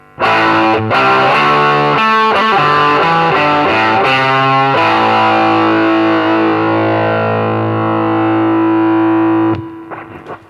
Записано все на советский конденсаторный микрофон (или в линию).
комбик без эквалайзера